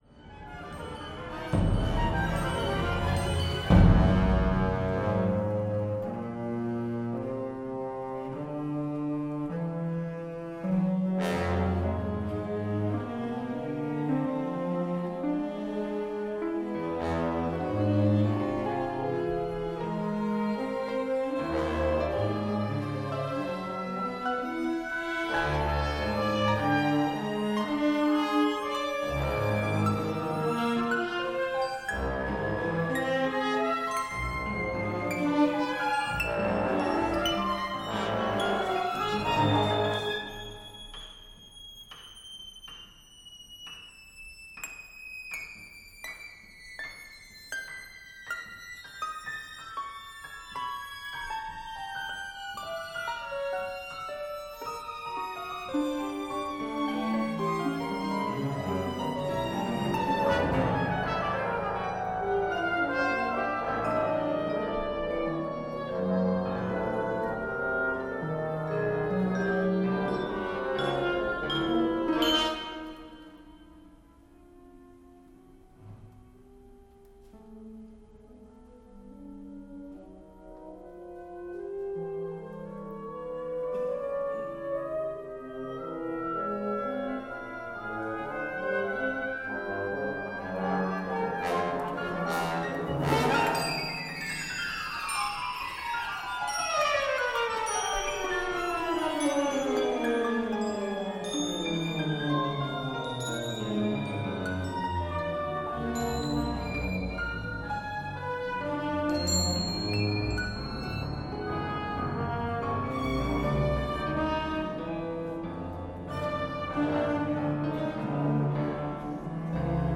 Excerpt, instrumentation of 5th Etude
Sinfonietta ensemble (16 musicians)
A longing for peace and fulfilment closes the piece.